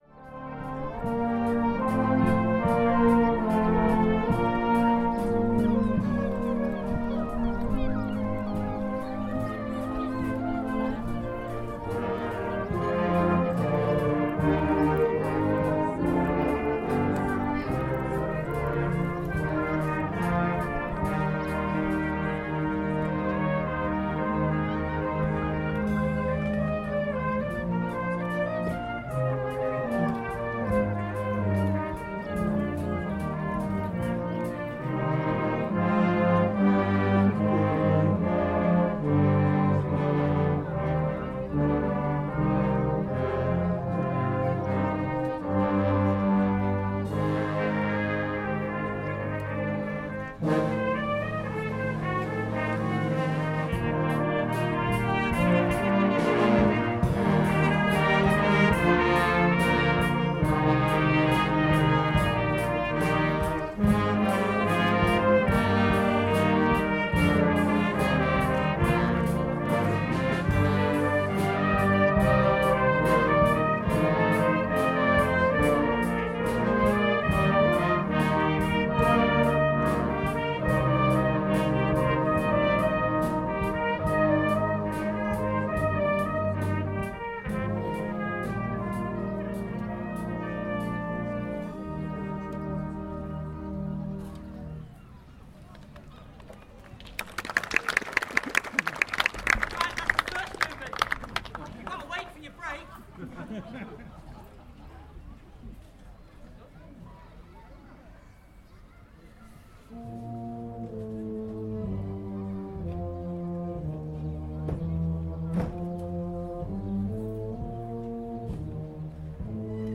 The great English seaside brass band
Brass band playing in a classic English seaside rain shower, Viking Bay, Broadstairs, Kent.